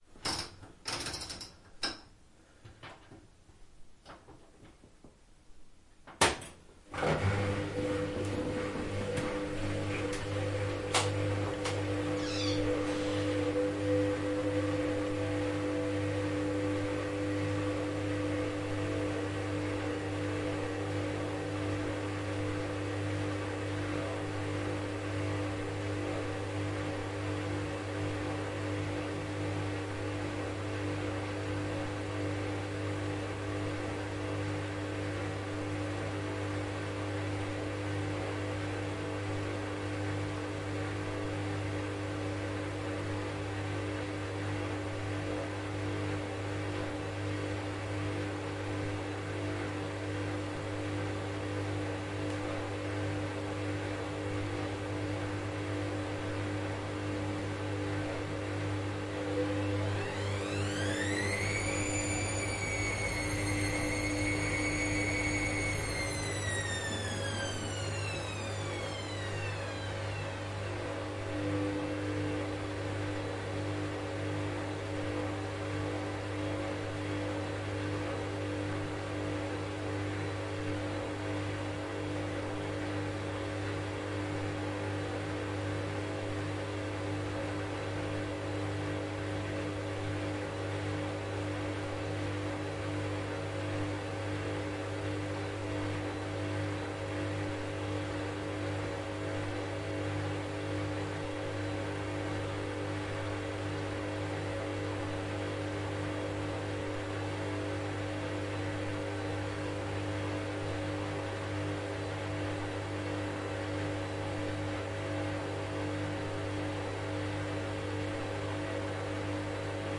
这是洗涤时的洗衣机，因为它洗涤里面的衣服。